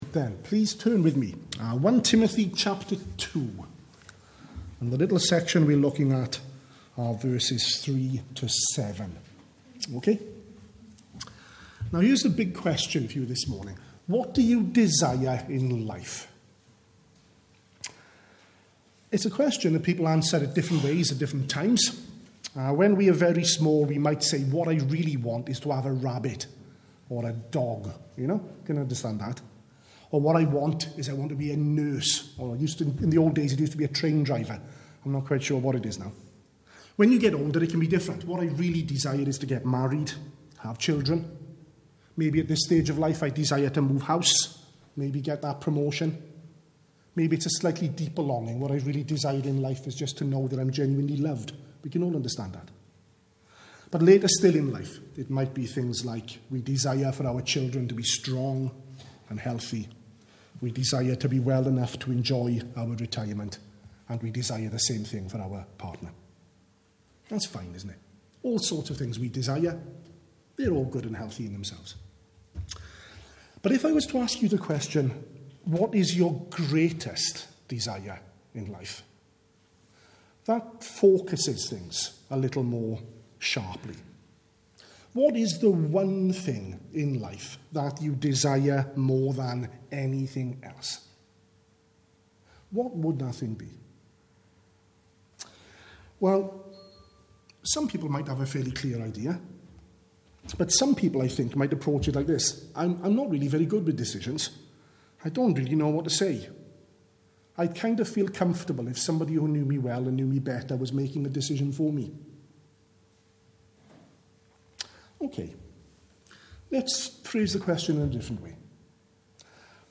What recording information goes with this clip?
at the morning service.